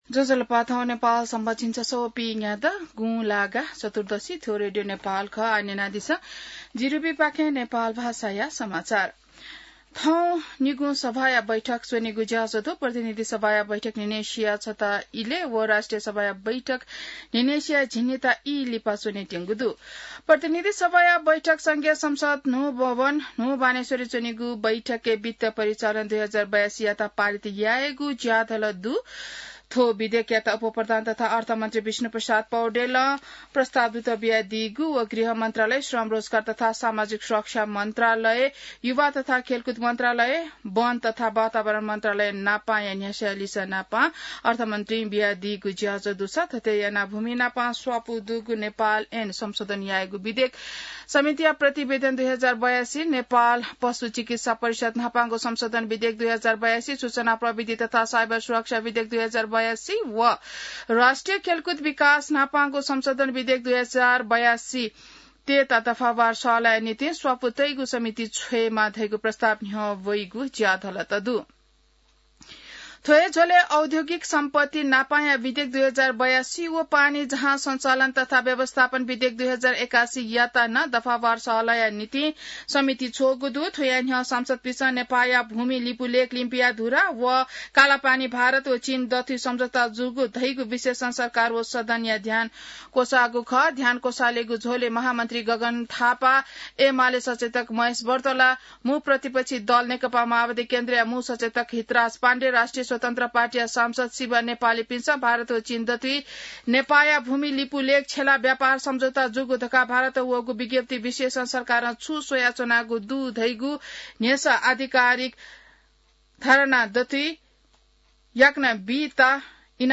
नेपाल भाषामा समाचार : ६ भदौ , २०८२